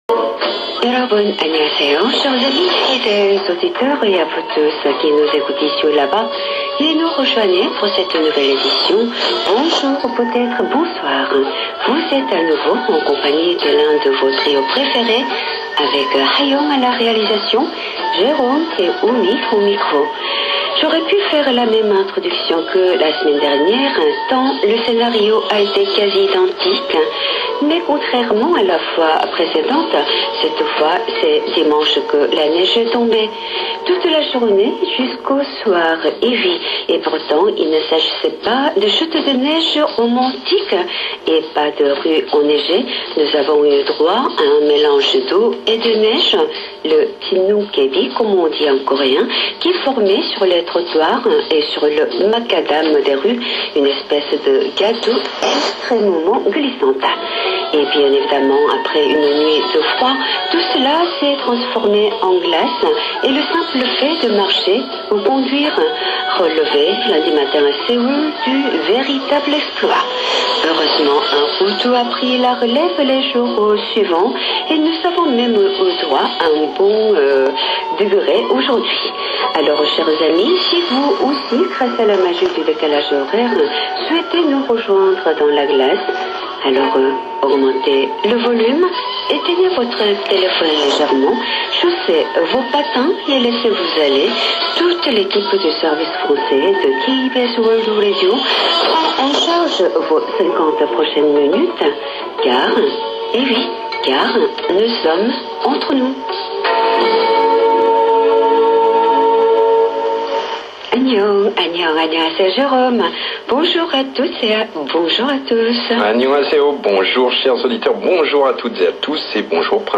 Enregistrements effectués sur le portatif et son antenne télescopique, des incidents constatés lors des transmissions radio de la KBS
6 minutes à la fin 16/11/2016 enregistrement de 14 minutes sur 3955KHZ ( 21h50à22h5 24 et 25/12/2016 enregistrement de 2 minutes chacunes 3955KHZ 4/02/2017 3 enregistrements de 2 a 4 minutes S/3955KHZ (